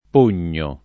[ p 1 n’n’o ]